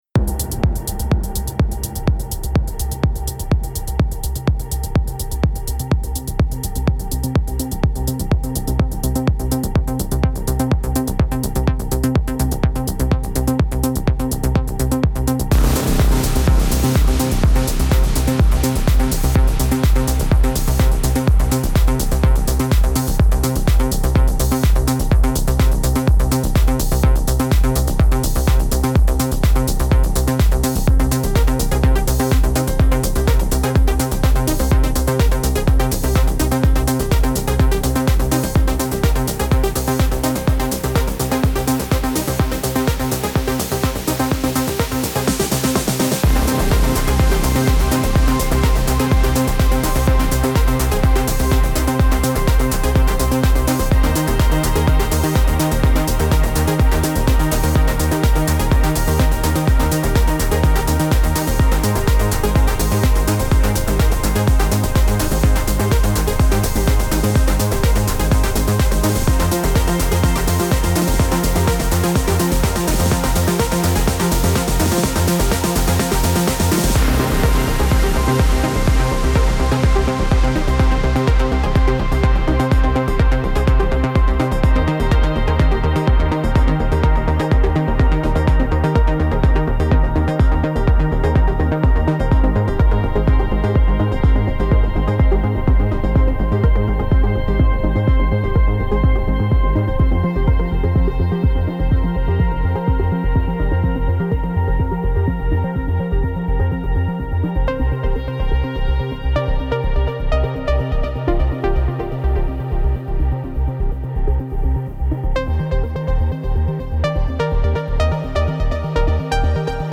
پراگرسیو هاوس
پر‌انرژی